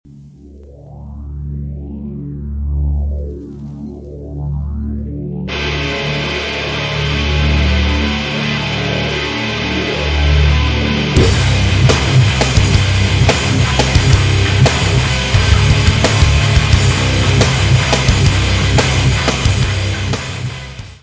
voice/guitar
drums
Turntables
Music Category/Genre:  Pop / Rock